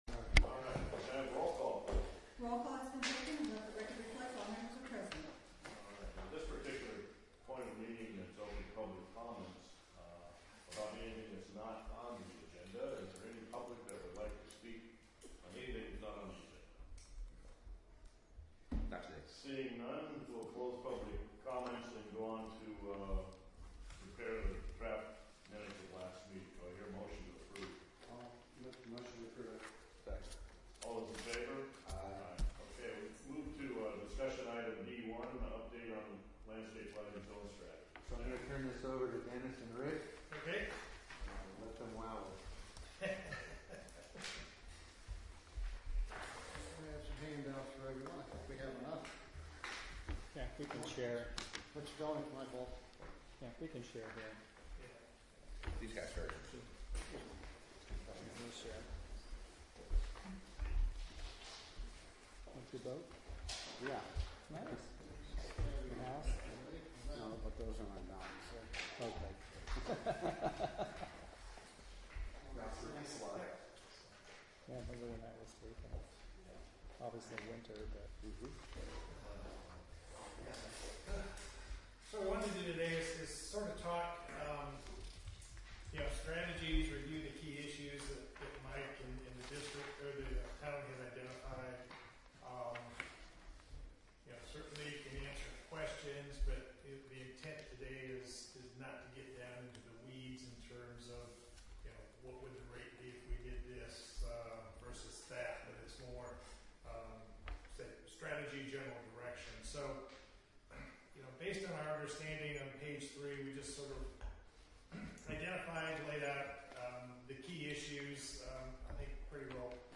Parks & Recreation Committee Meeting